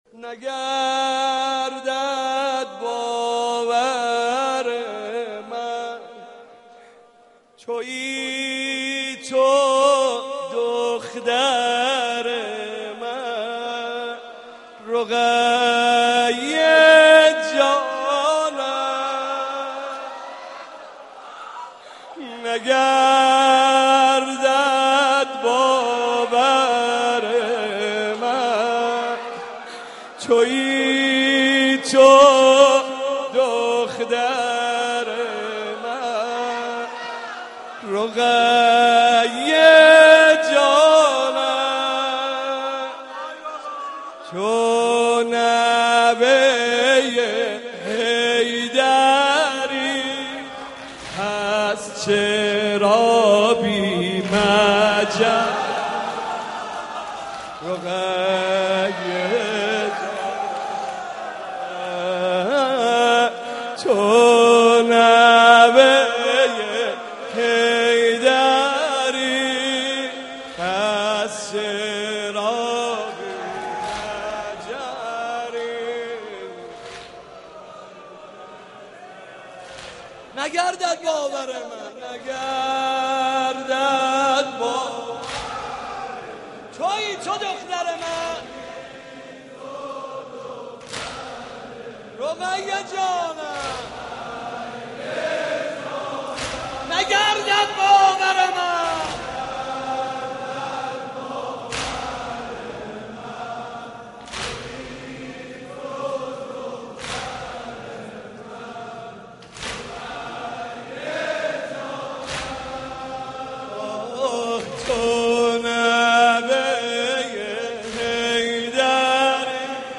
محرم 88 - سینه زنی 2